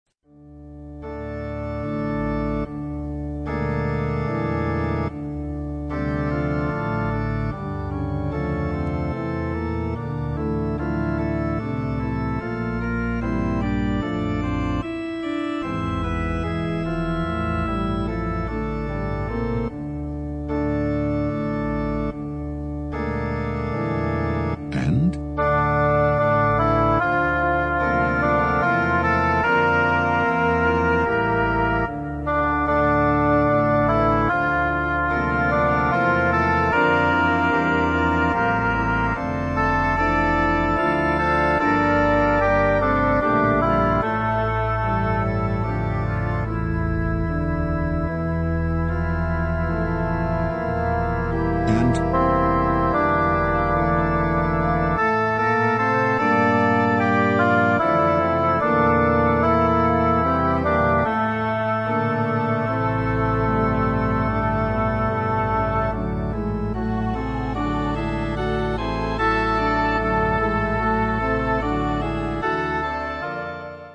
Alto
High Quality made by BBC Sound Engineer
Easy To Use narrator calls out when to sing
Don't Get Lost narrator calls out bar numbers
Vocal Entry pitch cue for when you come in